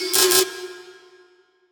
/audio/sounds/Extra Packs/Dubstep Sample Pack/FX/